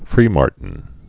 (frēmärtn)